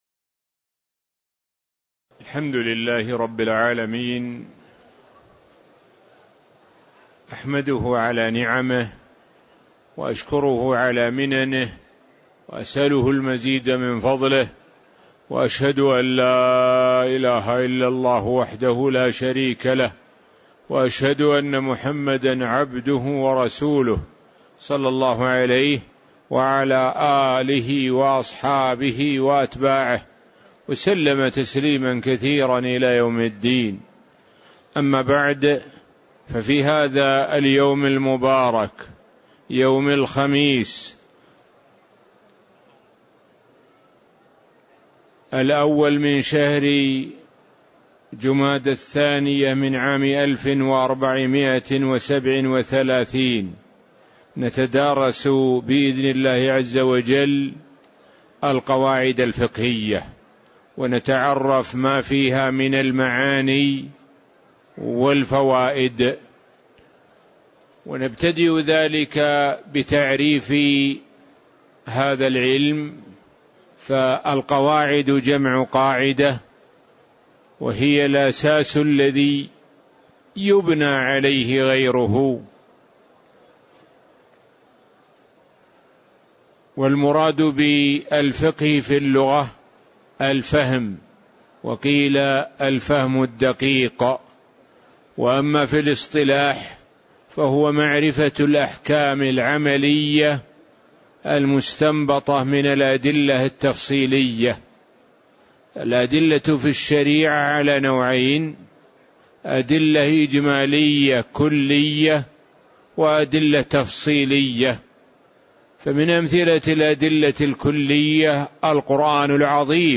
تاريخ النشر ١ جمادى الآخرة ١٤٣٧ هـ المكان: المسجد النبوي الشيخ: معالي الشيخ د. سعد بن ناصر الشثري معالي الشيخ د. سعد بن ناصر الشثري المقدمة (01)